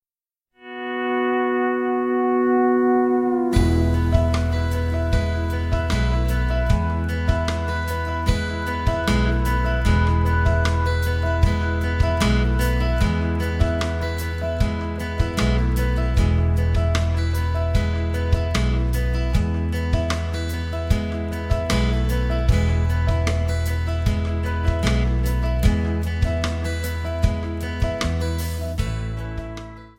MPEG 1 Layer 3 (Stereo)
Backing track Karaoke
Country, 2000s